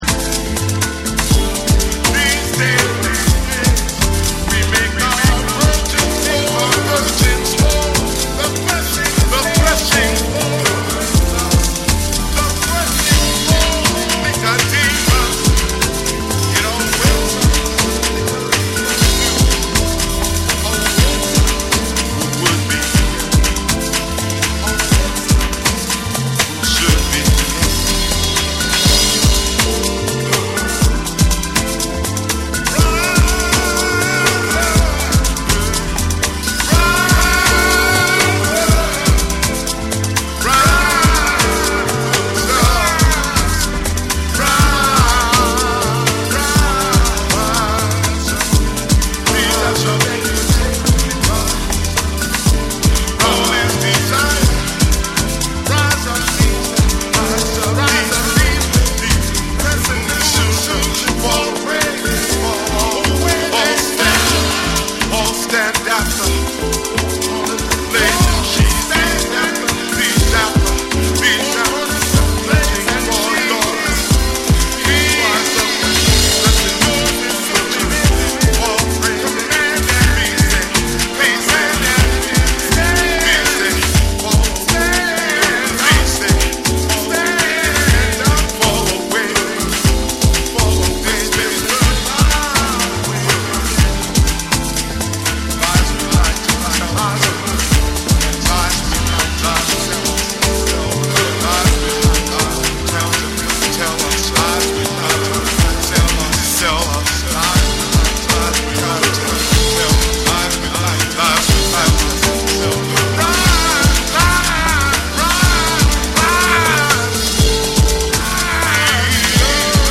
ジャズ、ブルース、ゴスペル、アフロの要素を繊細に織り交ぜた、ソウルフルで美しい壮大なディープ・ハウス作品。
TECHNO & HOUSE / ORGANIC GROOVE